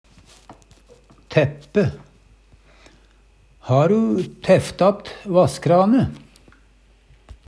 DIALEKTORD PÅ NORMERT NORSK tæppe teppe, stoppe, stenje Infinitiv Presens Preteritum Perfektum tæppe tæppe tæfte tæft Eksempel på bruk Har'u tæft att vasskrane? Hør på dette ordet Ordklasse: Verb Attende til søk